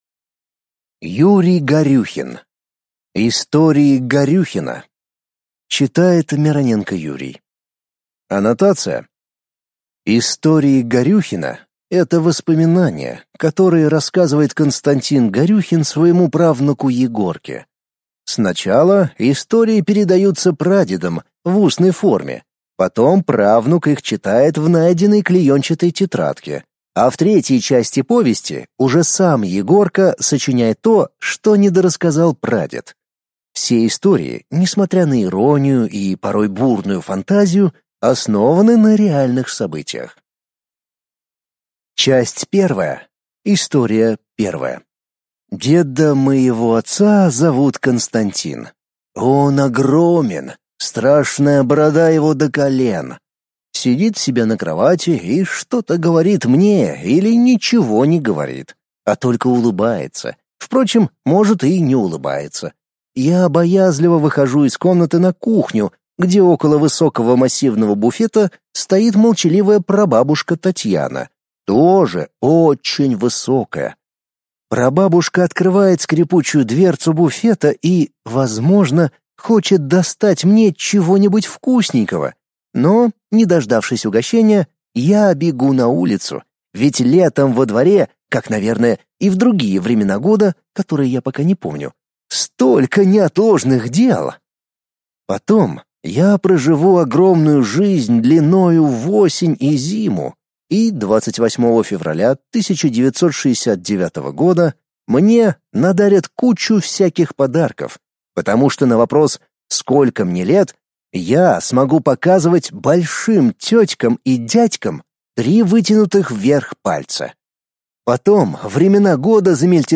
Аудиокнига Истории Горюхина | Библиотека аудиокниг